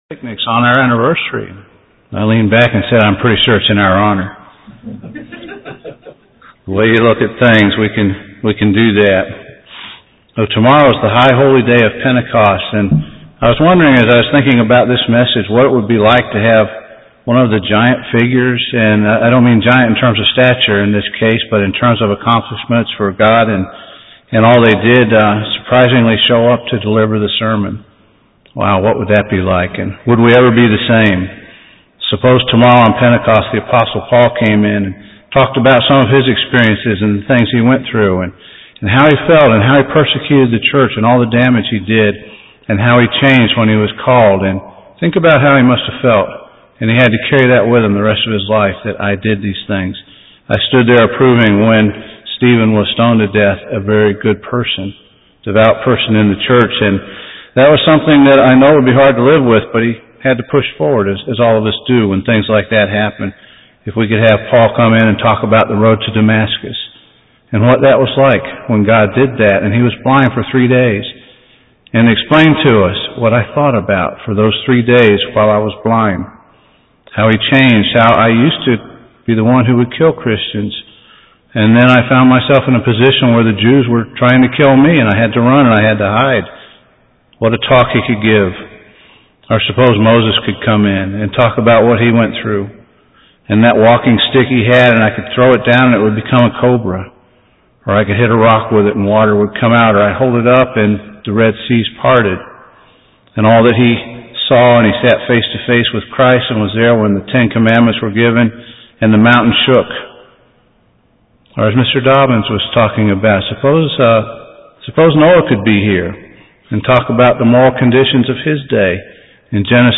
Peter, the Holy Spirit, and a Pentecost Sermon